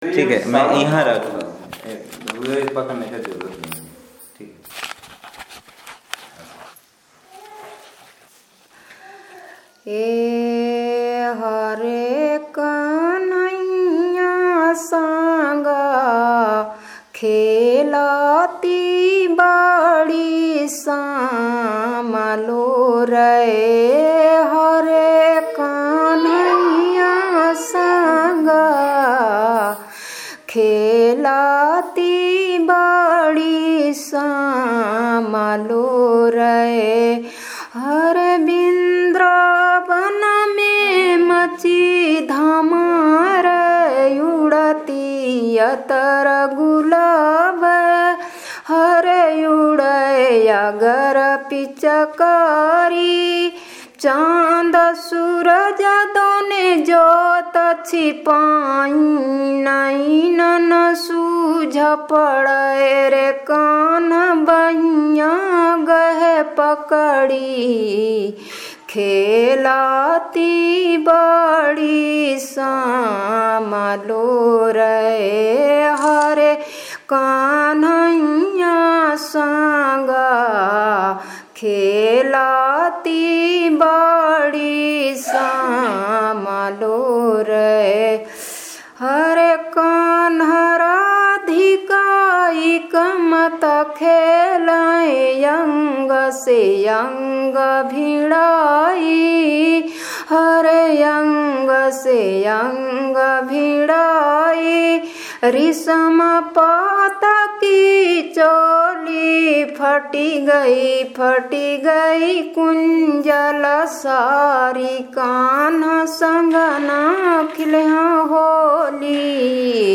Performance of a holy song